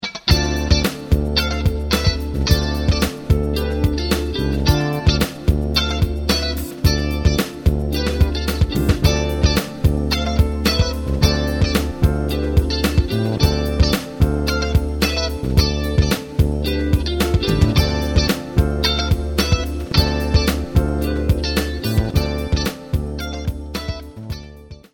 Ex. 8: pedale di Dm7 - Uso del delay #1
In questo esempio utilizziamo le triadi suonando in levare di ottavo sul movimento, con il delay impostato a 545ms. Considerando la velocità del brano di 110bpm, il delay suona in pratica a quarti.
esempio audio vi sembrerà però di sentire più triadi suonate in ogni battuta, mentre in realtà solo la prima è suonata: le altre sono le ripetizioni del delay. Dato che la triade viene suonata in levare di ottavo, anche le ripetizioni del delay si ripetono in levare.